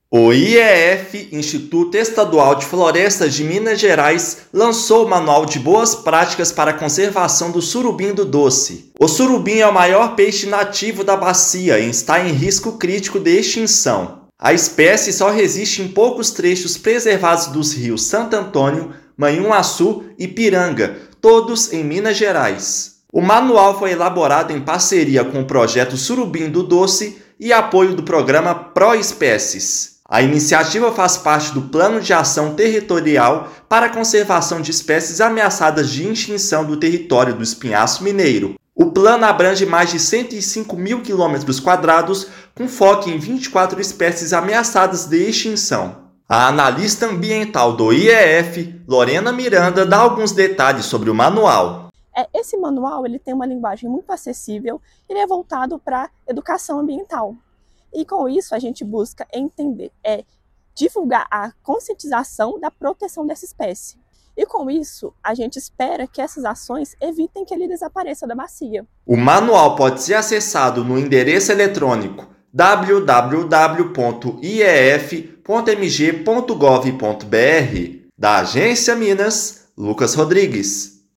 [RÁDIO] Projeto Surubim-do-Doce ganha manual para orientar ações de preservação
Publicação do IEF traz informações acessíveis e práticas sobre o maior peixe nativo da bacia do Rio Doce, hoje ameaçado de extinção. Ouça matéria de rádio.